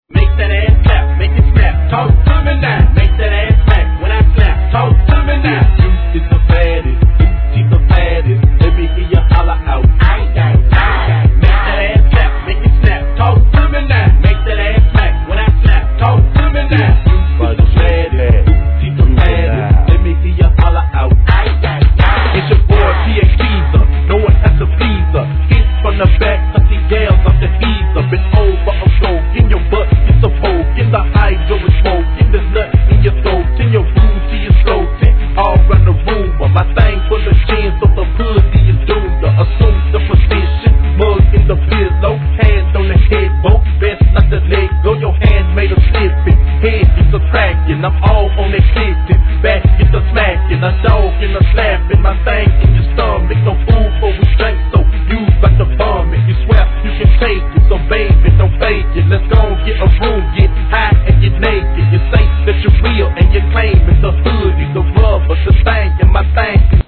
HIP HOP/R&B
チキチキサウンドにバックでの鐘の音色 や壮大なシンセメロディーが悲観な哀愁さを誘う2002年のDIRTY SOUTH!